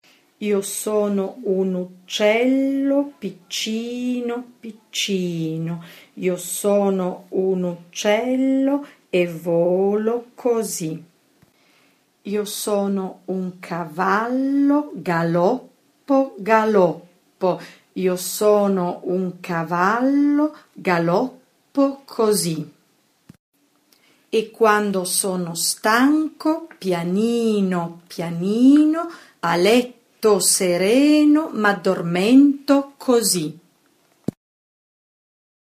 Io sono un uccello - Aussprachehilfe
iosonoganzAussprachehilfe_ital.mp3